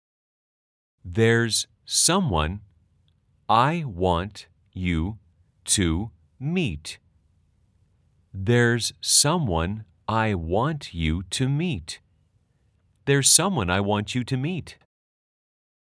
문장은 3번 반복되고 속도가 점점 빨라집니다.
/ 데어 써뭔 아이 / 워언츄트 / 미잇 /